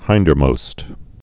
(hīndər-mōst)